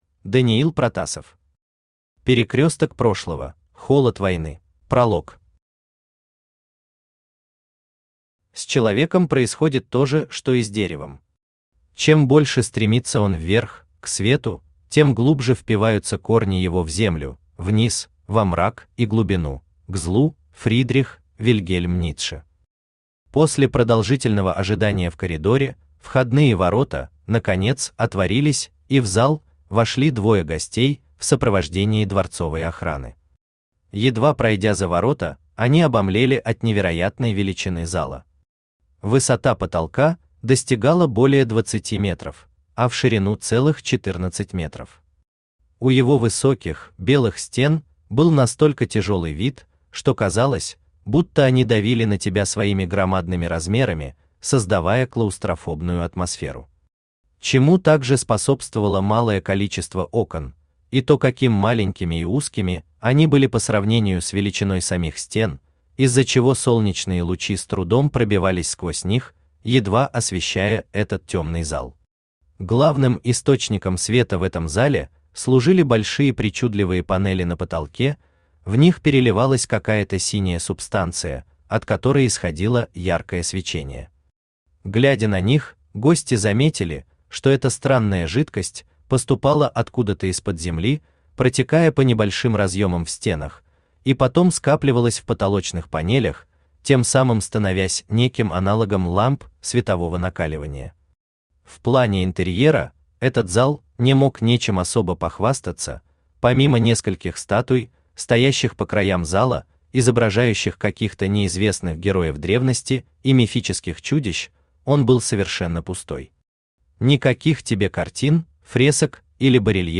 Аудиокнига Перекресток прошлого: Холод войны | Библиотека аудиокниг
Aудиокнига Перекресток прошлого: Холод войны Автор Даниил Витальевич Протасов Читает аудиокнигу Авточтец ЛитРес.